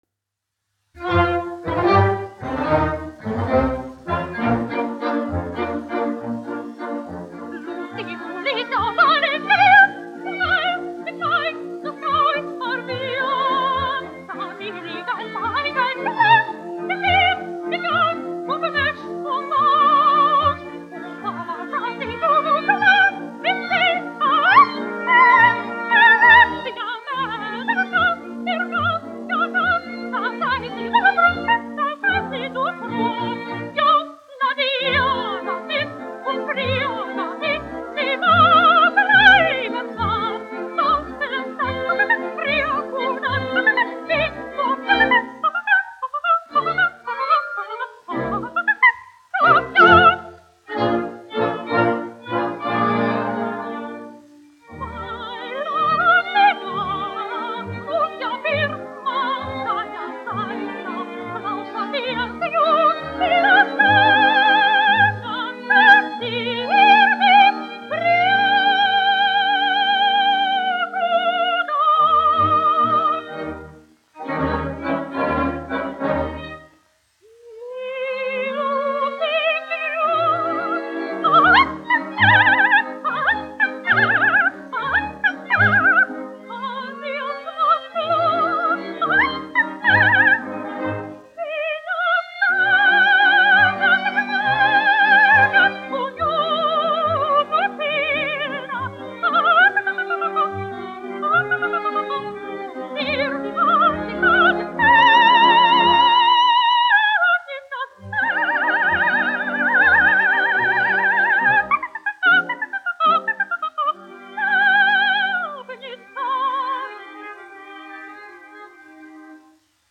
1 skpl. : analogs, 78 apgr/min, mono ; 25 cm
Valši
Dziesmas (augsta balss) ar orķestri
Skaņuplate
Latvijas vēsturiskie šellaka skaņuplašu ieraksti (Kolekcija)